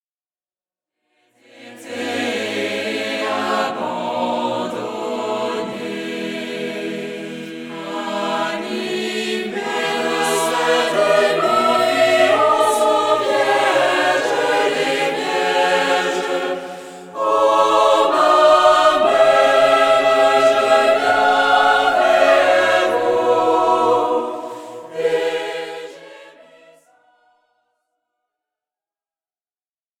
Format :MP3 256Kbps Stéréo